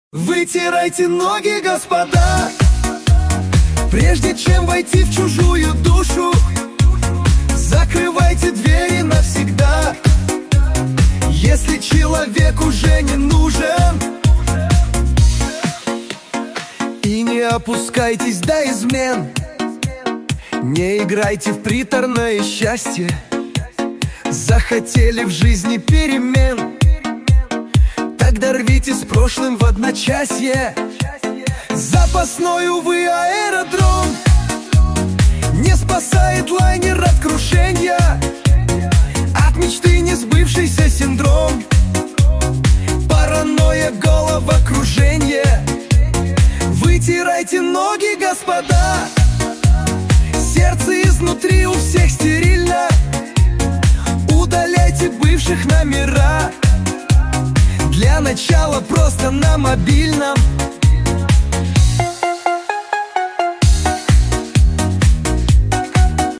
Суно ИИ кавер